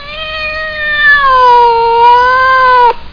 catlong.mp3